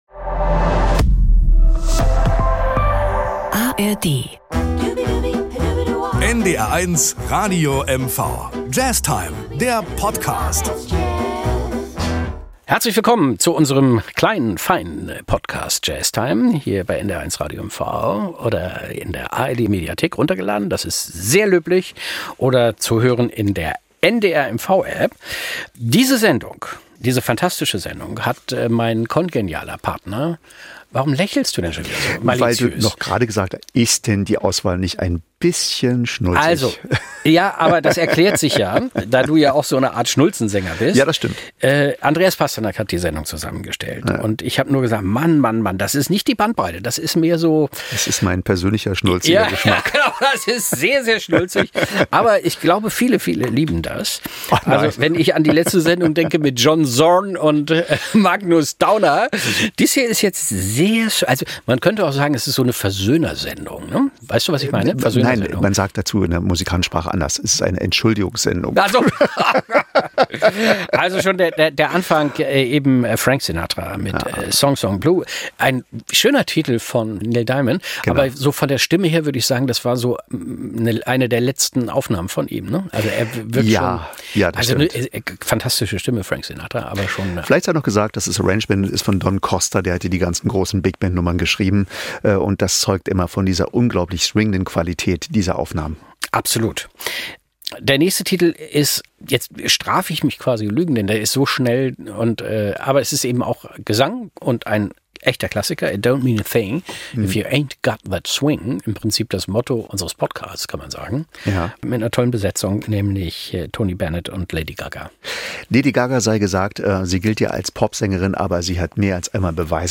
Ein Jazzstandard